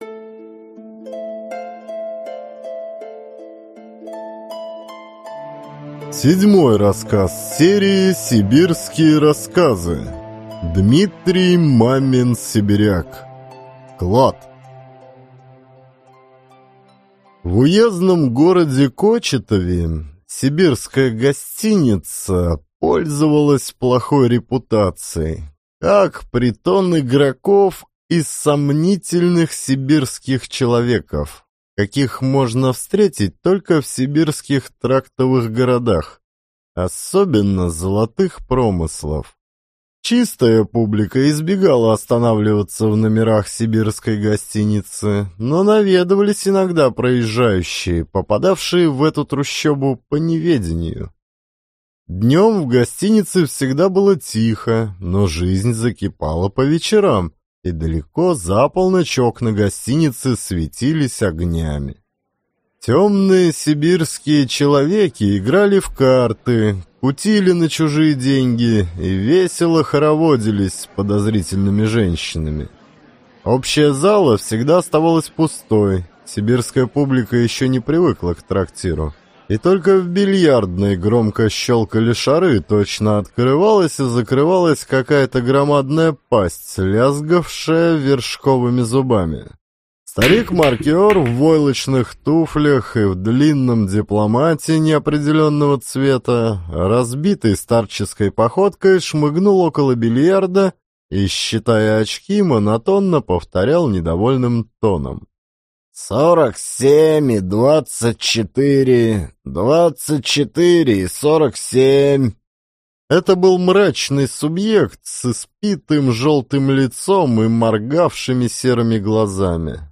Клад — слушать аудиосказку Дмитрий Мамин-Сибиряк бесплатно онлайн